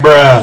normal-hitclap.mp3